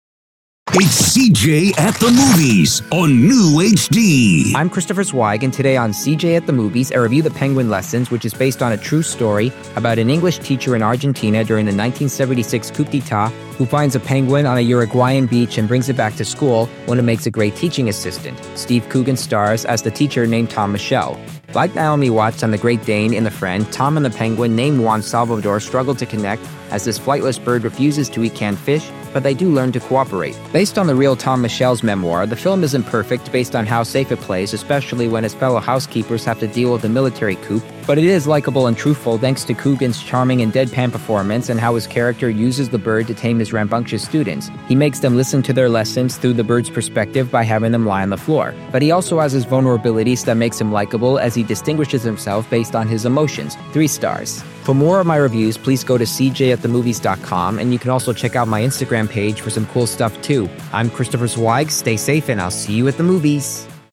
I’m part of a radio station known as NEWHD Radio, which not only hires people on the Autism spectrum, but also has me doing podcast movie reviews for them.